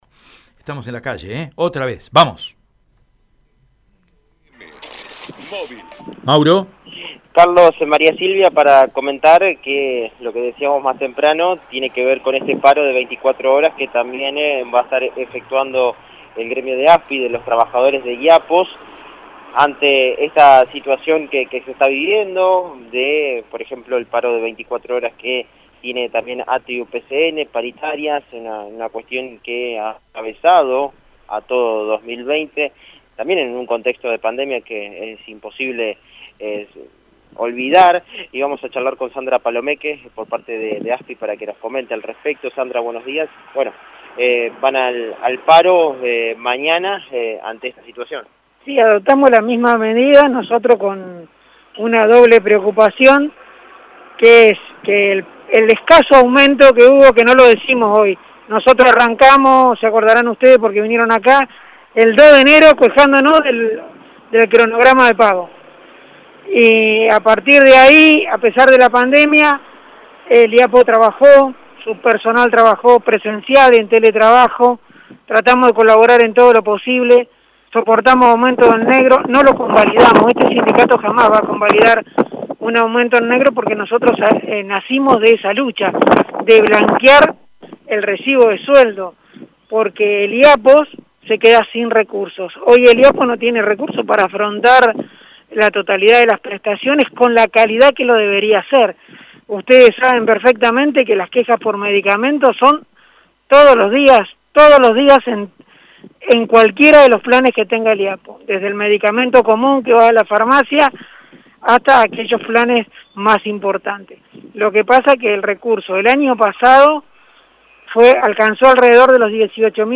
En diálogo con Radio EME, de la Asociación Sindical de Iapos expresó que se adoptó esta medida porque «por el escaso aumento que hubo, que no lo decimos hoy, nosotros arrancamos el 2 de enero quejandonos del cronograma de pago y a partir de ahí a pesar de la pandemia el Iapos trabajó, tratamos de colaborar en todo lo posible, soportamos aumentos en negro, no lo convalidamos, este sindicato jamas va a convalidar un aumento en negro porque nosotros nacimos de esa lucha».